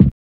HARD KNOCKS.wav